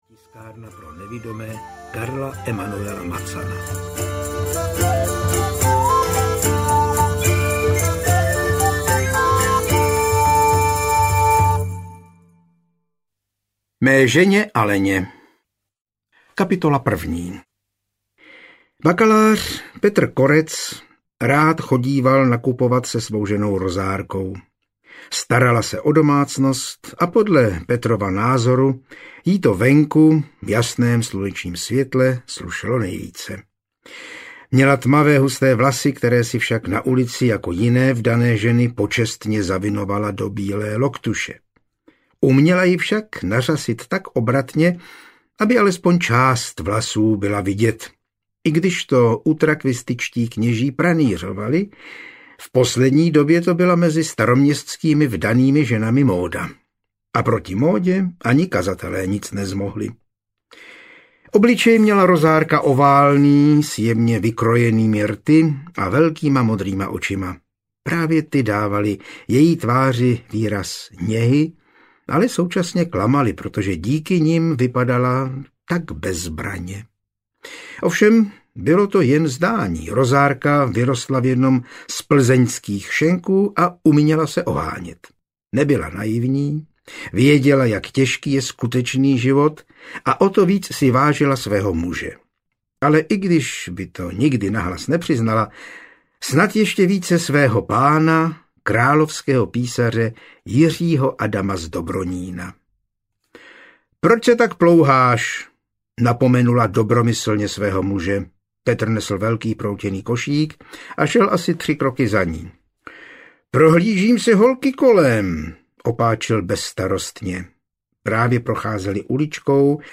Mrtvý posel audiokniha
Ukázka z knihy
• InterpretJan Hyhlík